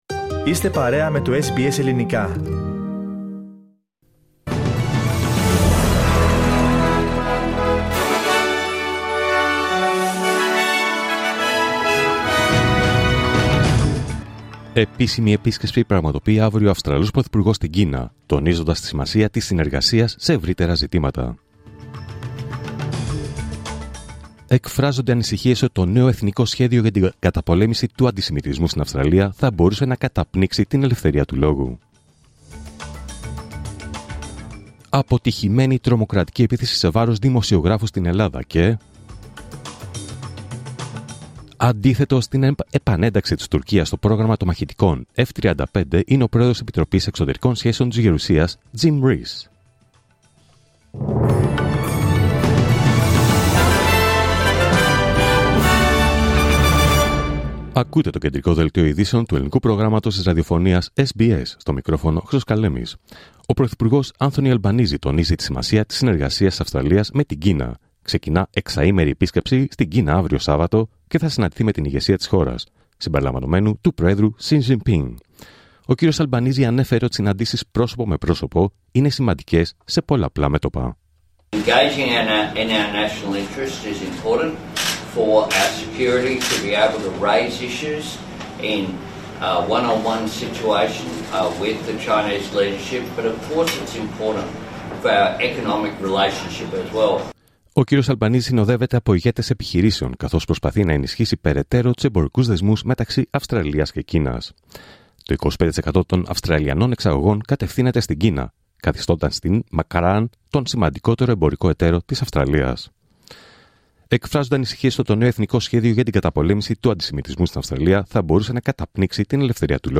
Δελτίο Ειδήσεων Παρασκευή 11 Ιουλίου 2025